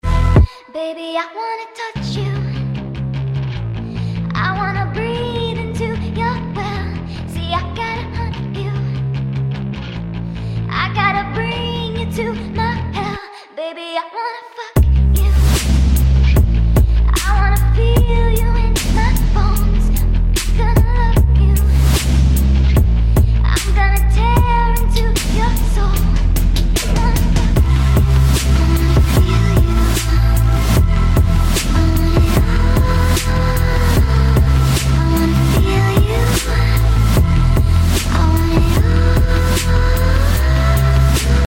Zwischenfrucht sähn mit dem John